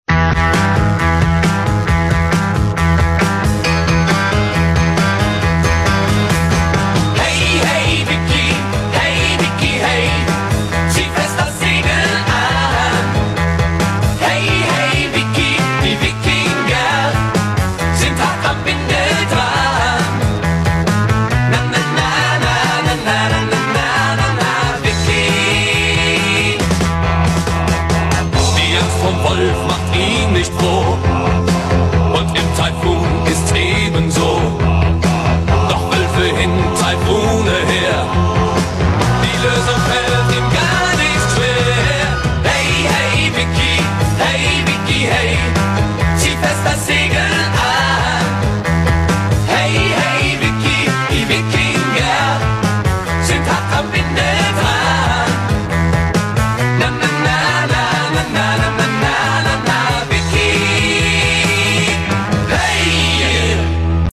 •Intro•